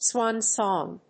アクセントswán sòng